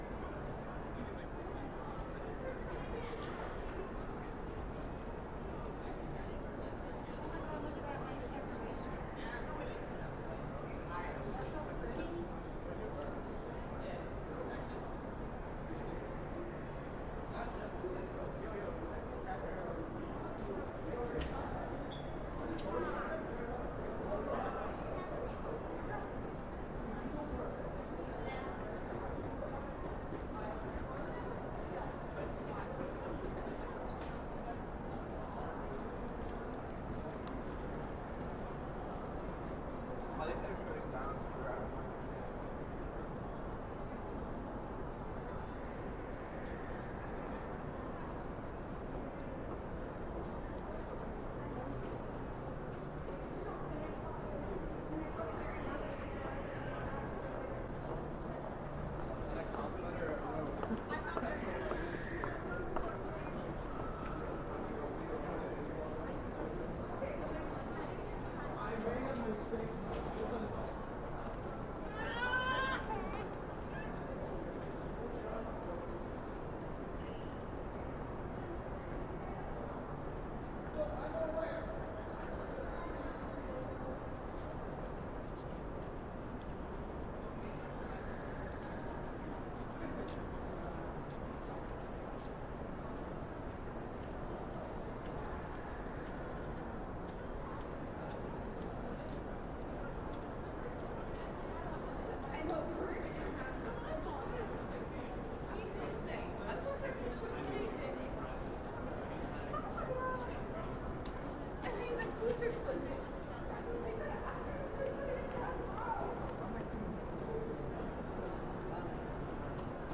MallInCanada.mp3